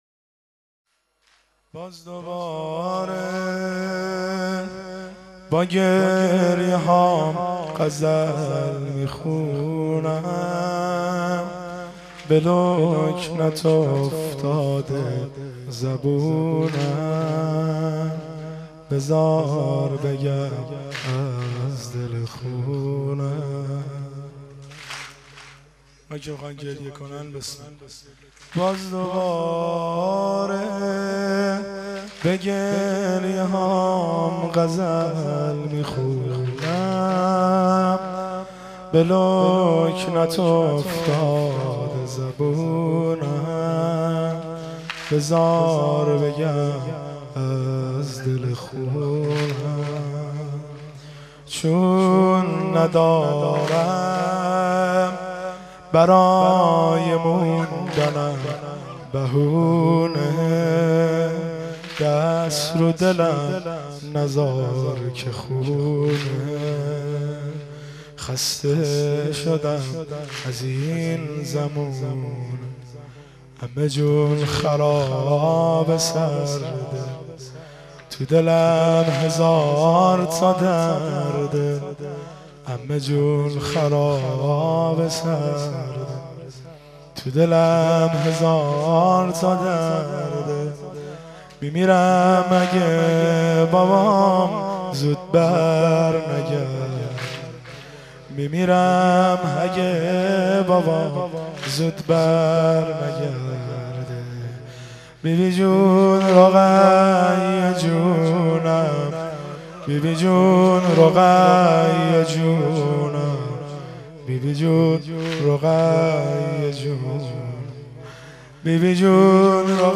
سنگین شب 3 محرم الحرام 1390 هیئت محبان الحسین
دانلود سبک سنگین